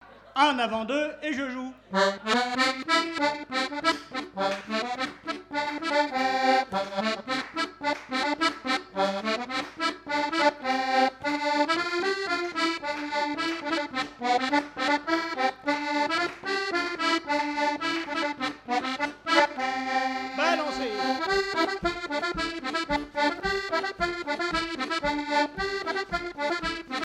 danse : branle : avant-deux
Fête de l'accordéon
Pièce musicale inédite